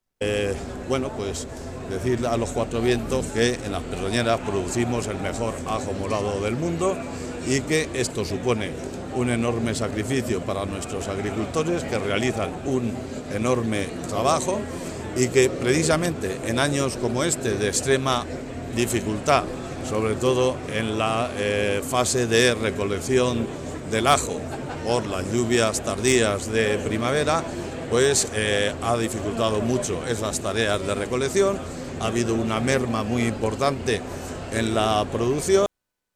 El alcalde, José Manuel Tortosa, destacó la importancia de celebrar este tipo de eventos que se llevan a cabo gracias al apoyo de empresas, cooperativas y otras entidades que ap